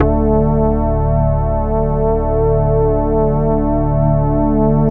SYNTH GENERAL-2 0002.wav